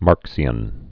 (märksē-ən)